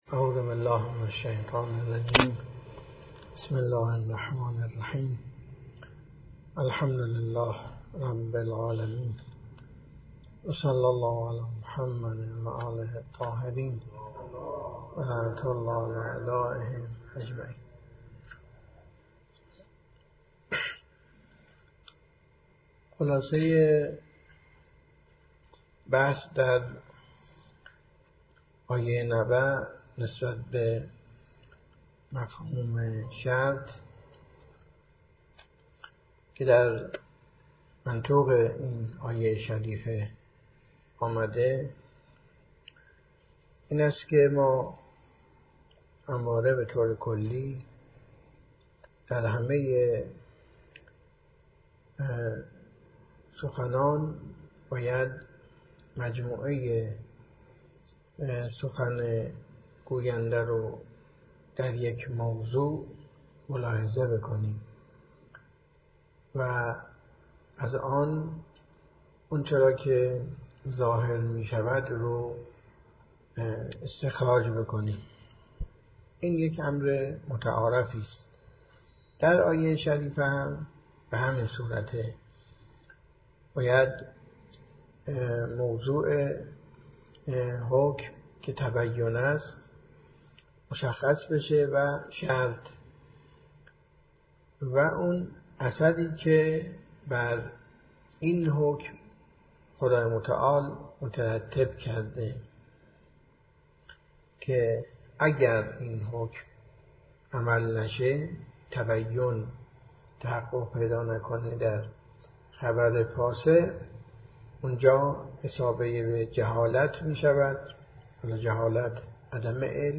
درس خارج